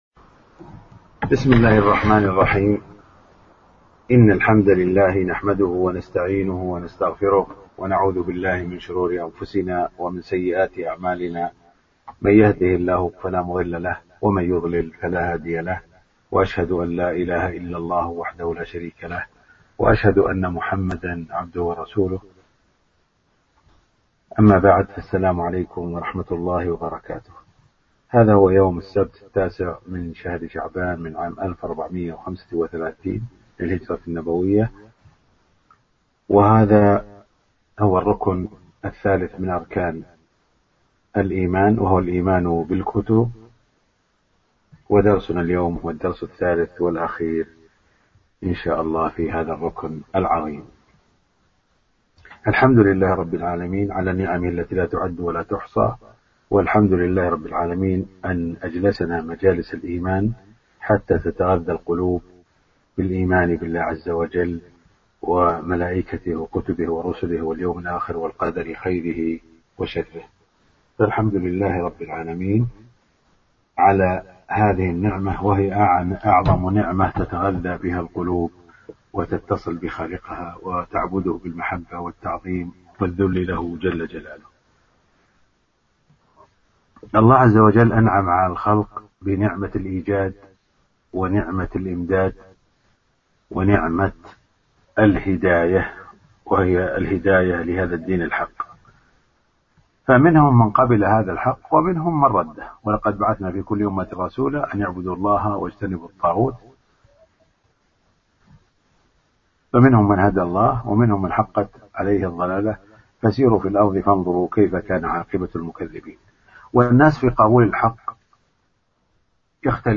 فقه القلوب 3-الإيمان بالكتب (الدرس الثالث).mp3